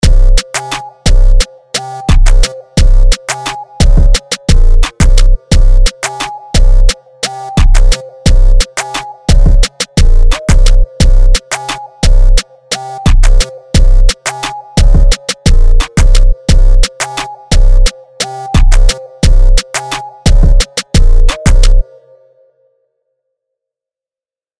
still needs a lot of work. it feels a bit empty right now, but for something i whipped up in about, like, an hour, it's pretty good. i tried to go for a brokencyde vibe here with a tiny pinch of jerk.
If this is an intro, it's too long